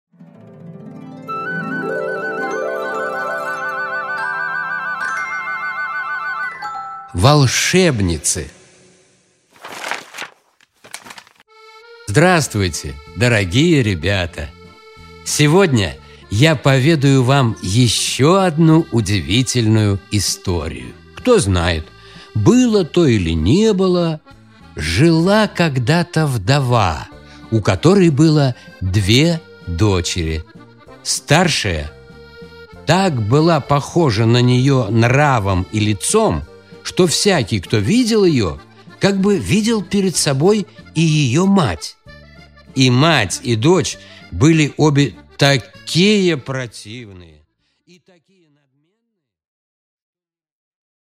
Аудиокнига Волшебницы